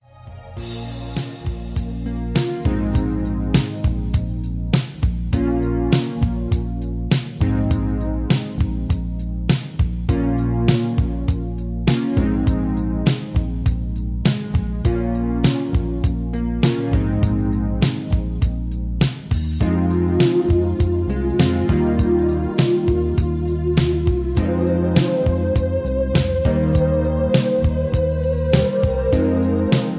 Karaoke MP3 Backing Tracks
Just Plain & Simply "GREAT MUSIC" (No Lyrics).
karaoke mp3 tracks